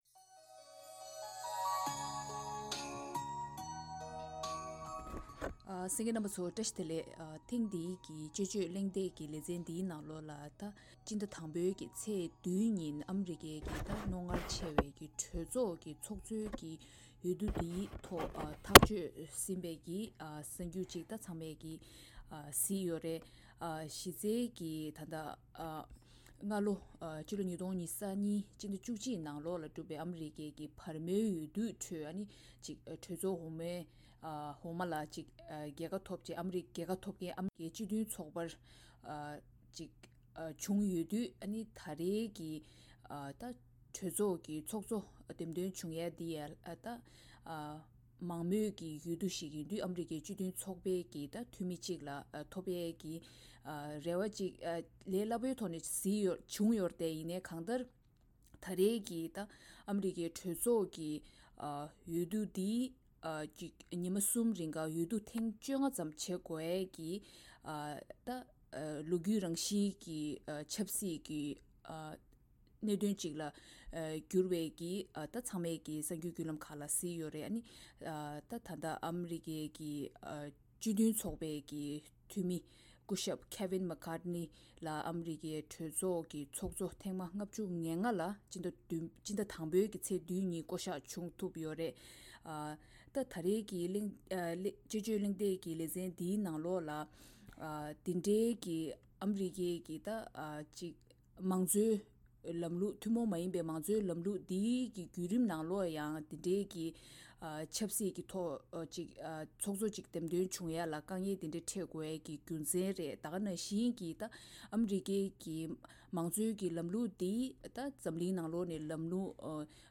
ཐེངས་འདིའི་དཔྱད་བརྗོད་གླེང་སྟེགས་ཀྱི་ལས་རིམ་ནང་།